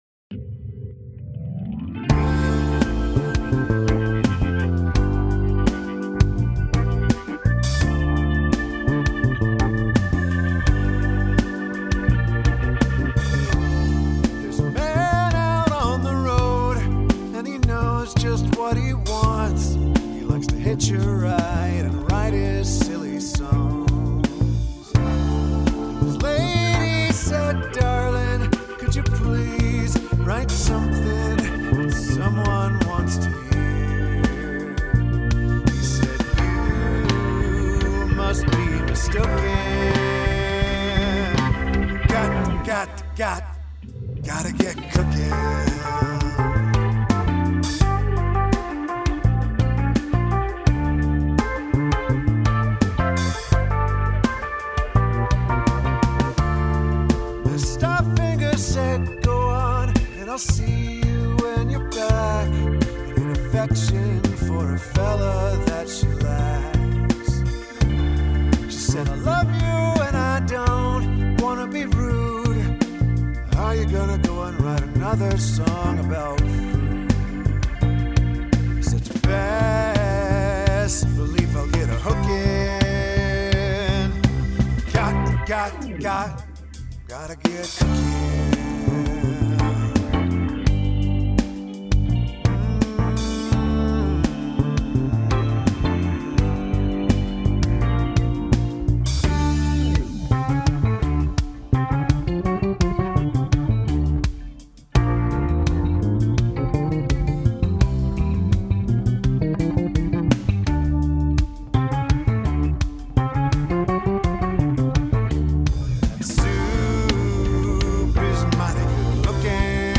Loving the keys.